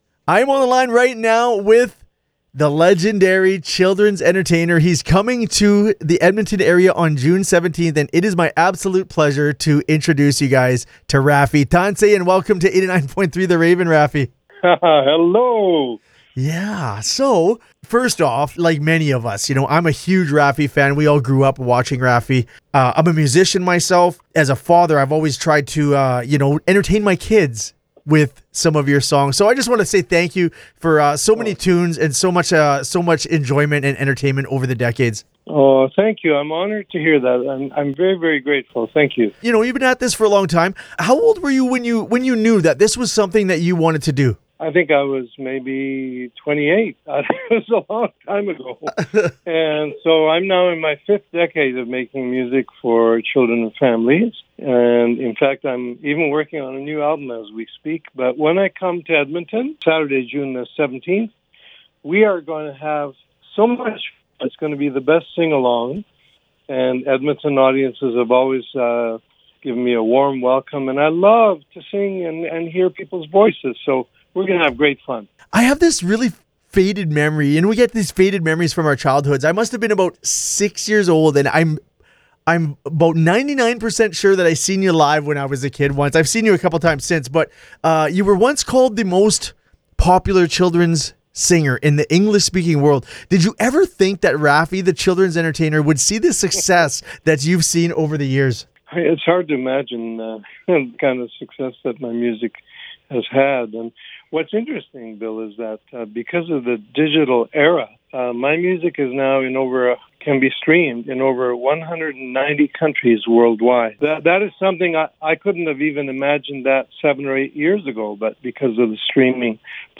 raffi-full-for-web-no-music.mp3